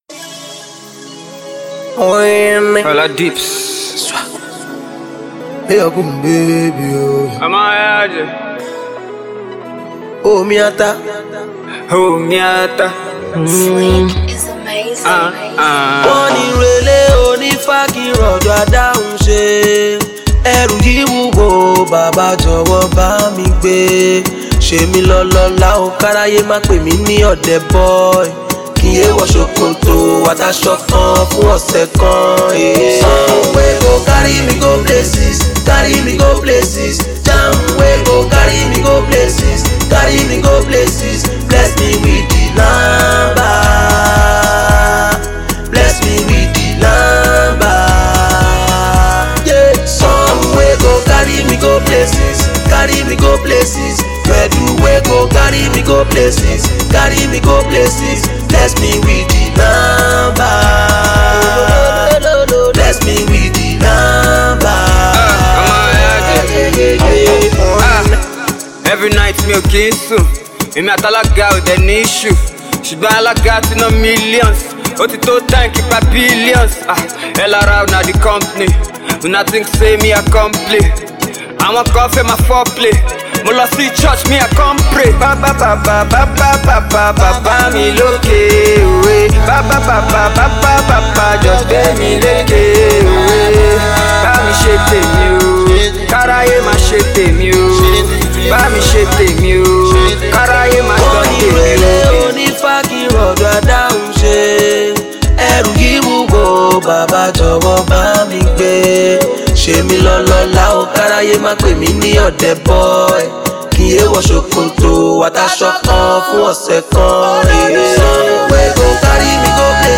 rapper
catchy single
melodious single